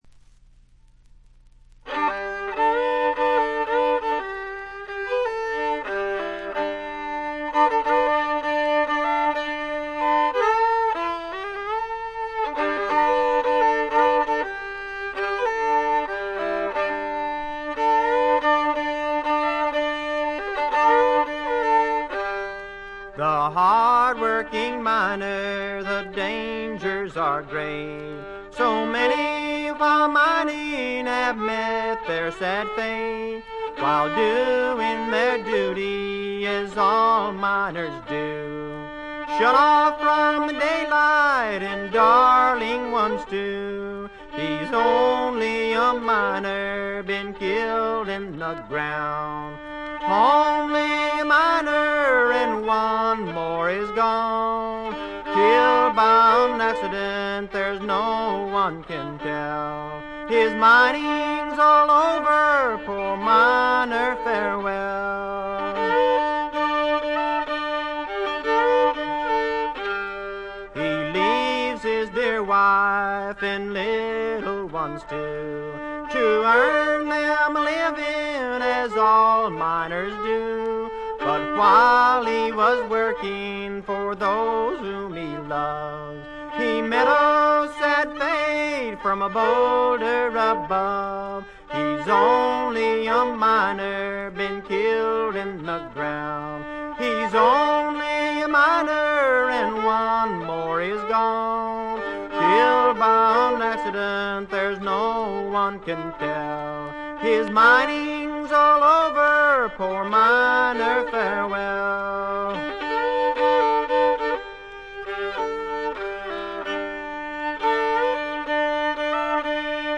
原初のブルーグラスの哀愁味あふれる歌が素晴らしいです！
試聴曲は現品からの取り込み音源です。